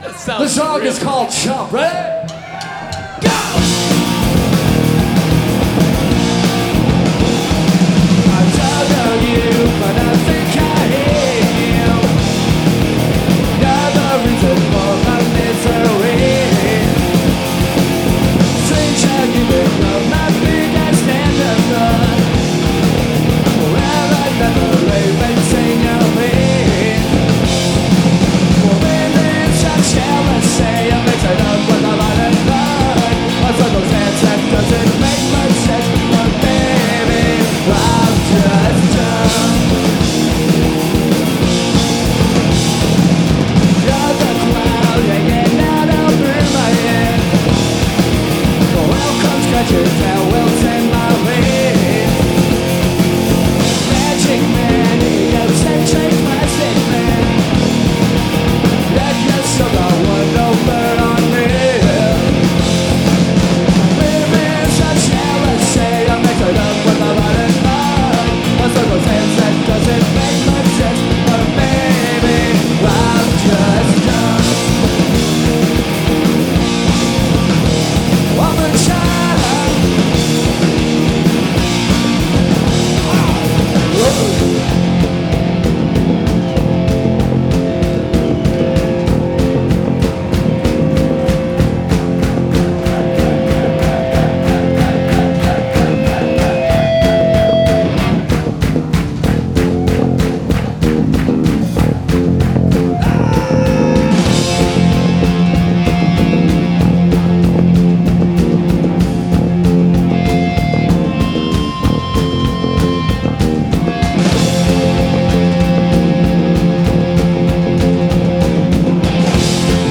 Live at Garatge Club, Barcelona 1994